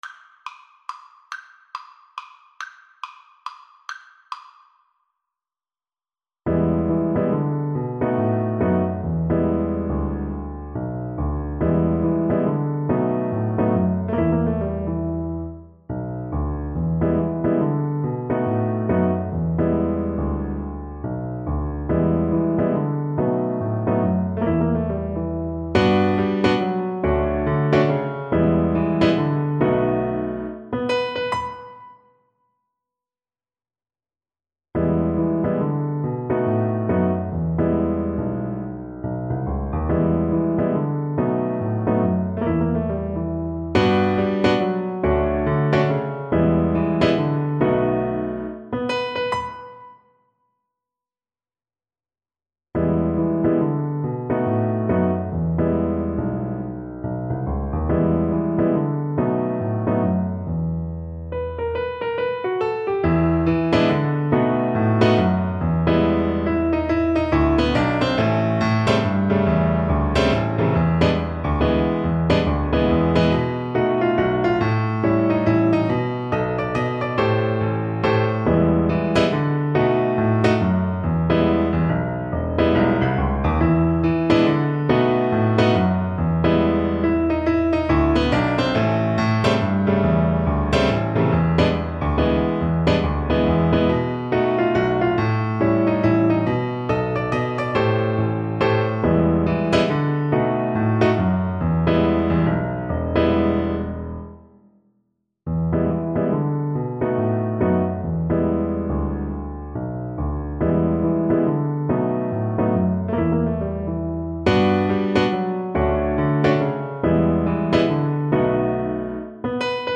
3/4 (View more 3/4 Music)
=140 Fast swing